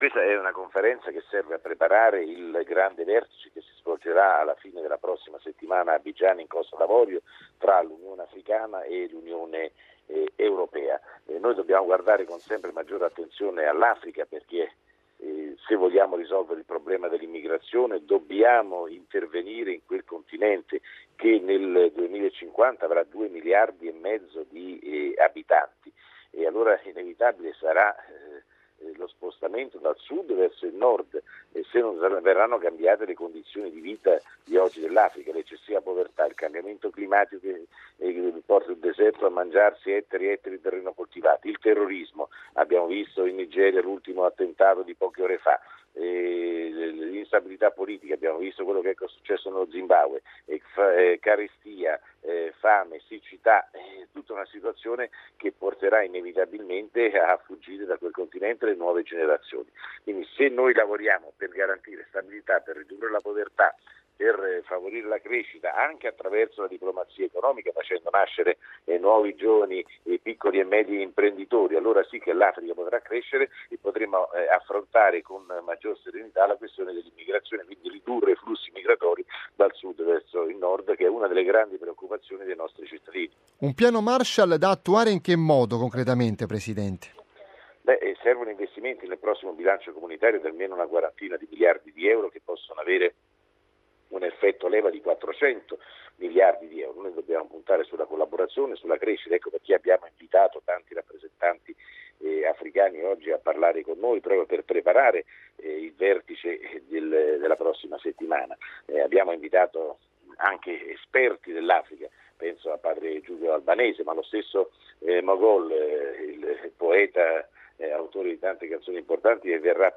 Non si tratta di regalare soldi ma generare un volano di crescita economica e sociale vantaggioso non solo per gli africani ma anche per gli europei” Ascolta e scarica in podcast l'intervista ad Antonio Tajani: